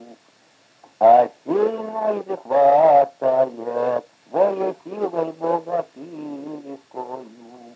Отличные от нормативных окончания в именительном падеже единственного числа мужского рода прилагательных – безударная флексия –ый в соответствии с литературной -ой
/а-б’е-зу”-мно-йот хвааа”-ста-йет мо-ло-до”й же-но”й кра-сааа”-в’и-цей/